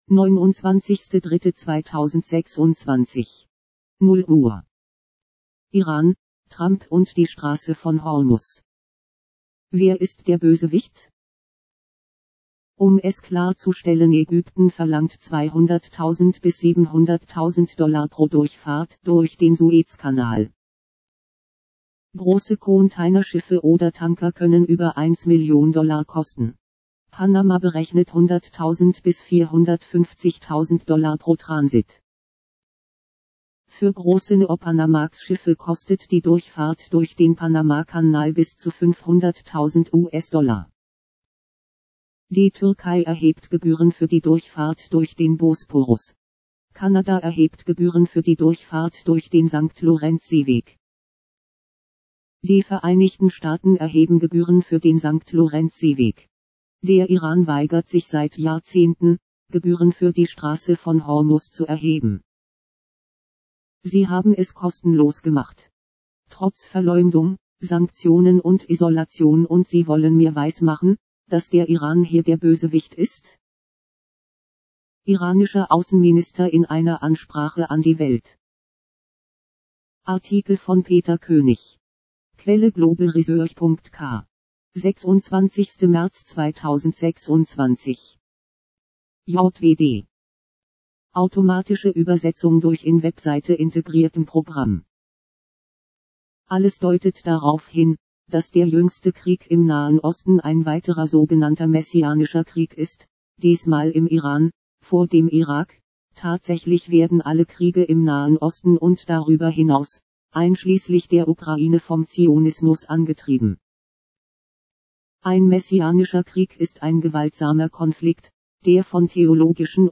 .vorlesen | Popupfenster �ffnen mp3 | erzeugt mit Pediaphon | JWD